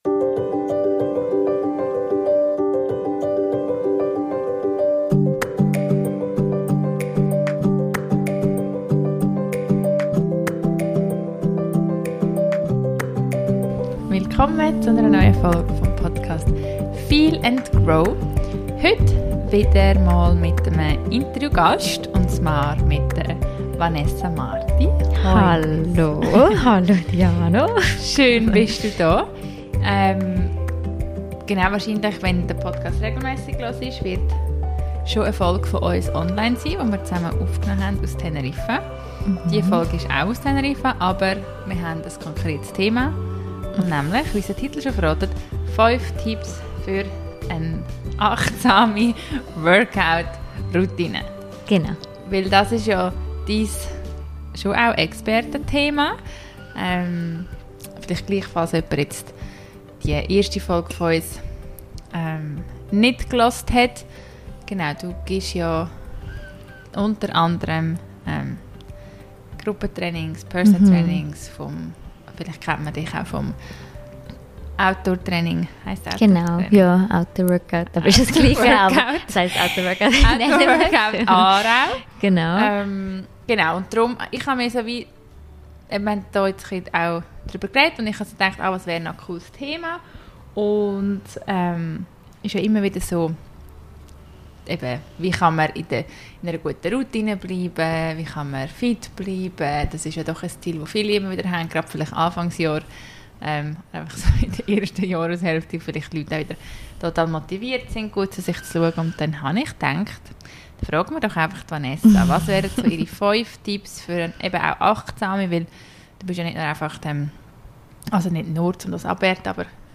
in Teneriffa aufgenommen